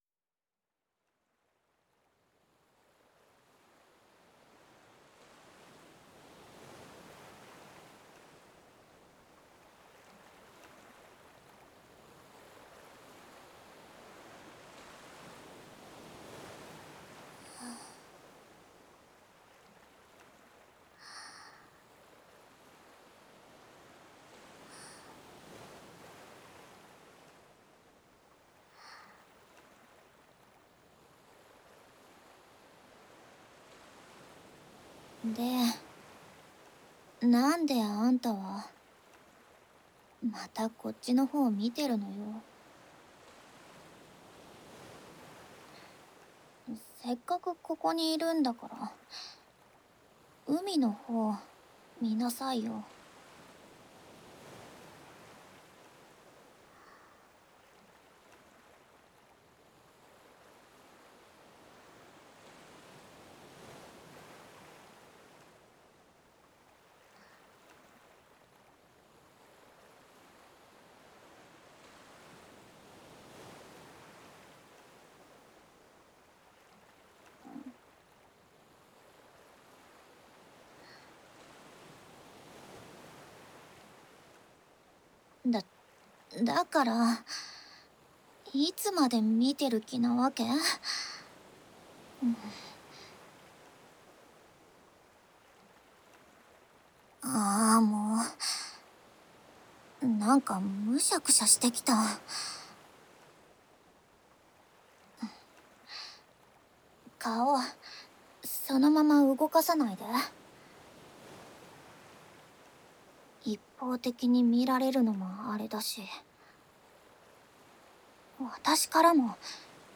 【简体中文版】海姐妹・三女儿 随着浪涛声让没那幺老实的傲娇少女来疗愈你的ASMR【CV.津田美波】 - ASMR Mirror